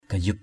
/ɡ͡ɣa-zup/ (đg.) tháo chạy. jaguk gayup nao nagar jg~K gy~P _n< ngR quân xâm lược tháo chạy về nước.